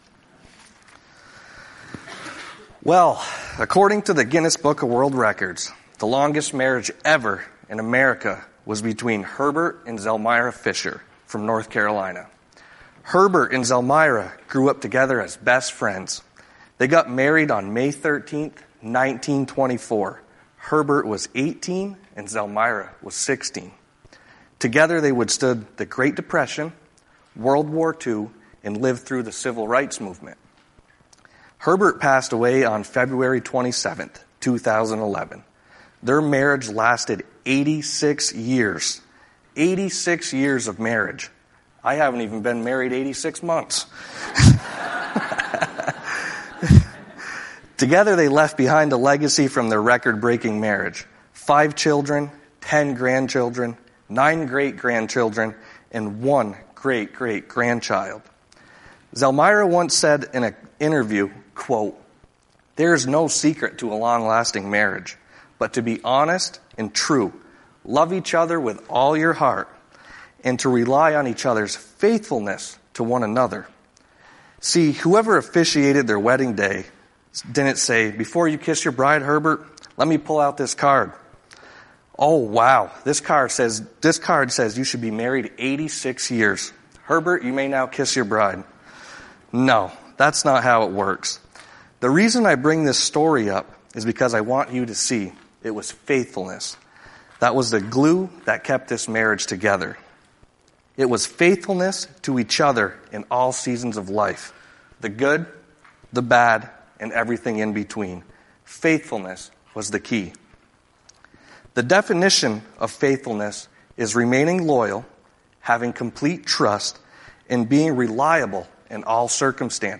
Sermons preached at Redeeming Grace Church, Georgia VT.